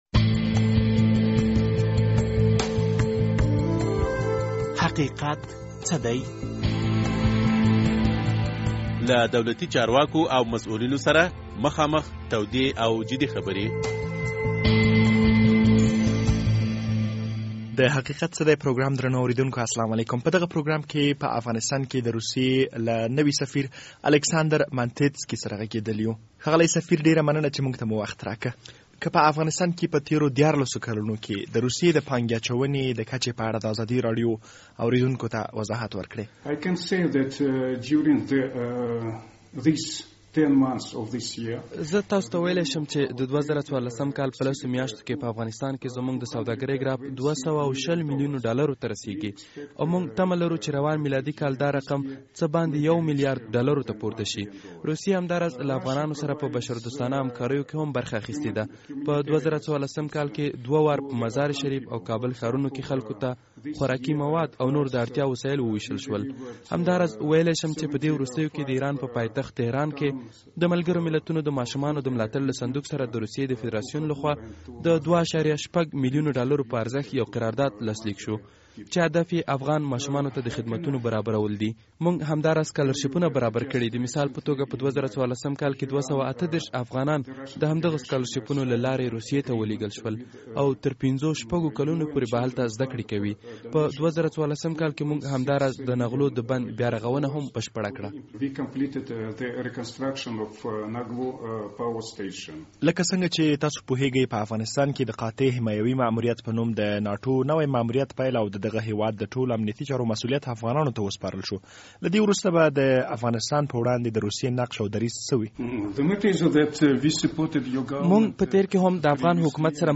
د حقیقت څه دی په پروګرام کې دا ځل په افغانستان کې د روسیي له نوي سفیر الکساندرمانتیتسکي سره خبرې شوي.